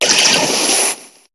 Cri de Triopikeur dans Pokémon HOME.